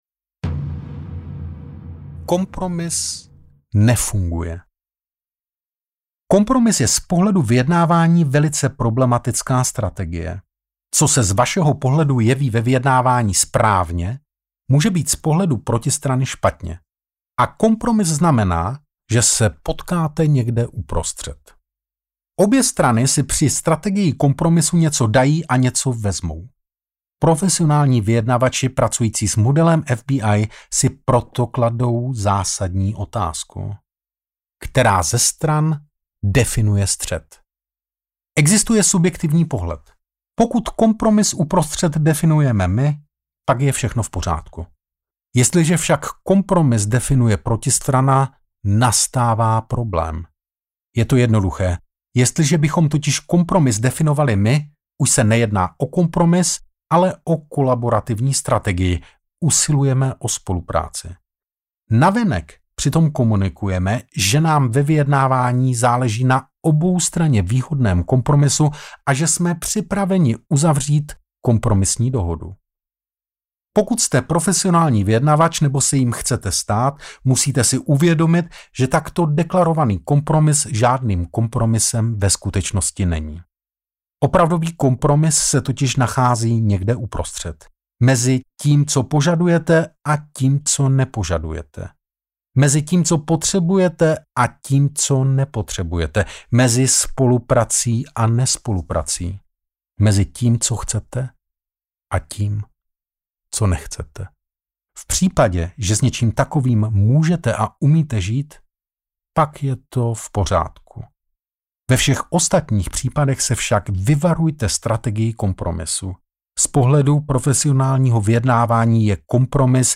Umění vyjednat cokoliv audiokniha
Ukázka z knihy
umeni-vyjednat-cokoliv-audiokniha